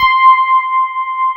Index of /90_sSampleCDs/USB Soundscan vol.09 - Keyboards Old School [AKAI] 1CD/Partition A/13-FM ELP 1